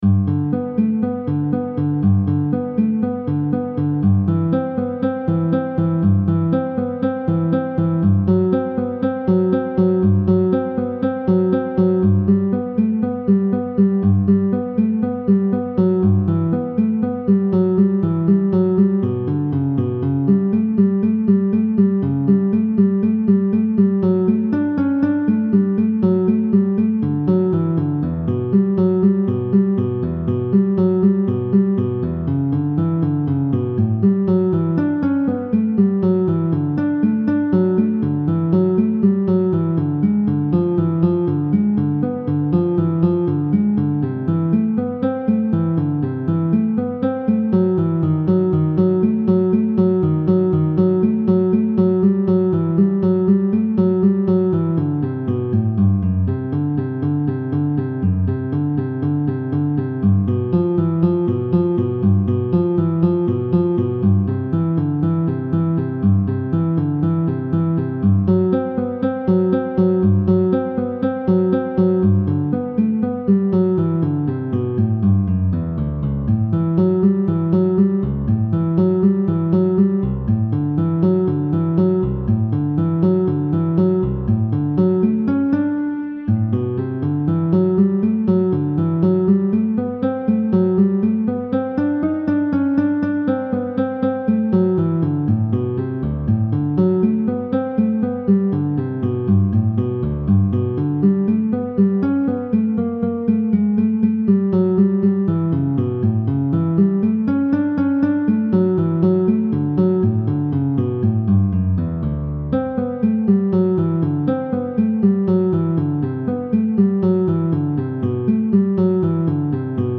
guitar audio track